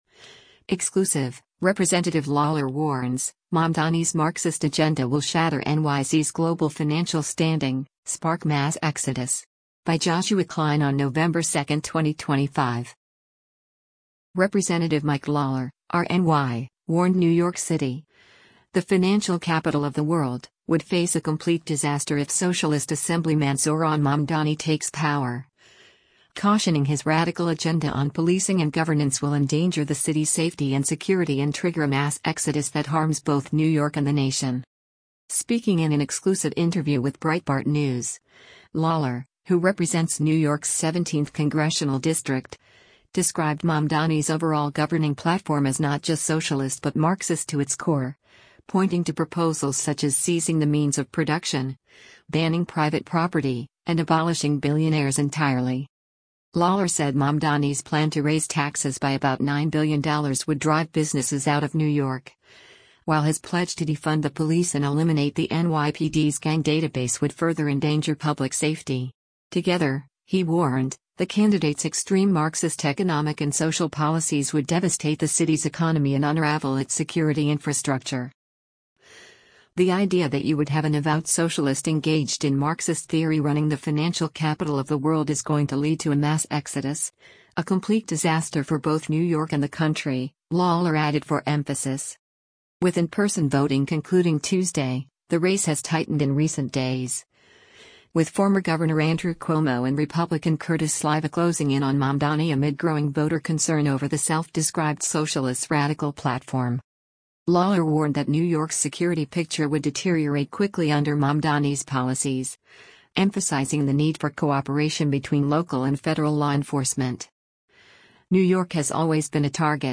Speaking in an exclusive interview with Breitbart News, Lawler — who represents New York’s 17th Congressional District — described Mamdani’s overall governing platform as “not just socialist but Marxist to its core,” pointing to proposals such as “seizing the means of production,” banning private property, and abolishing billionaires entirely.